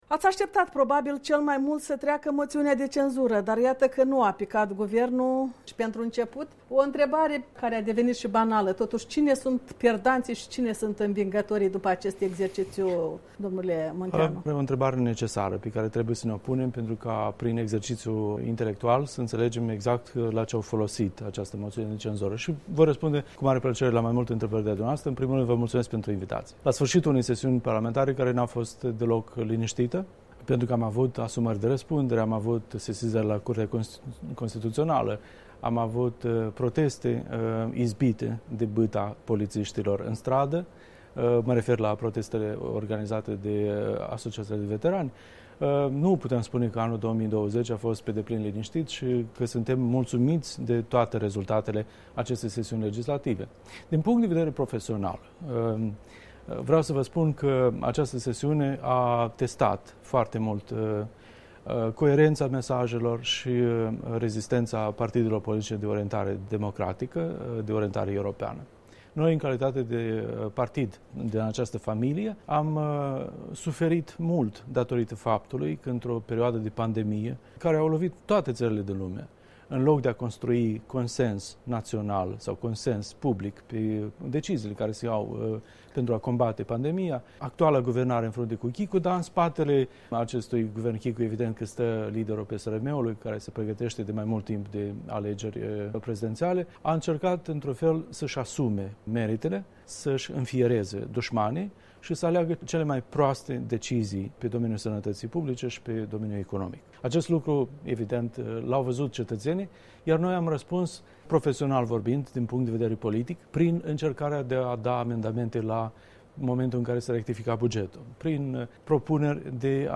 Interviu cu Igor Munteanu